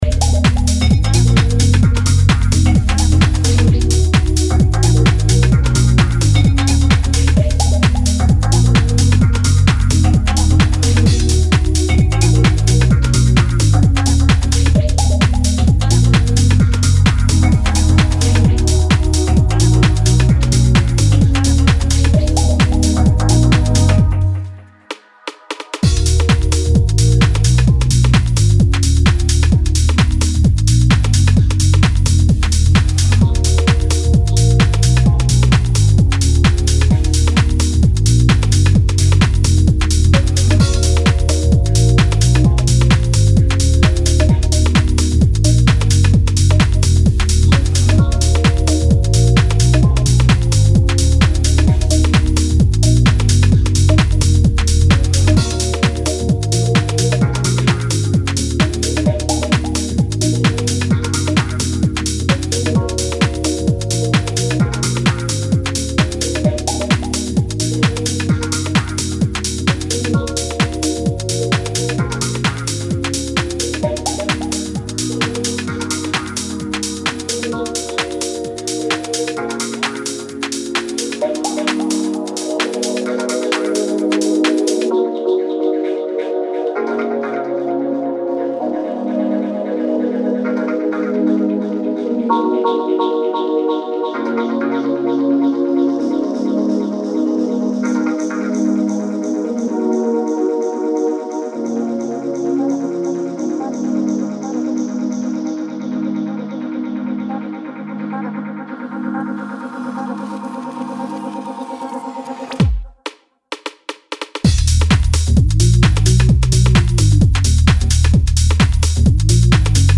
energetic & groove laden cuts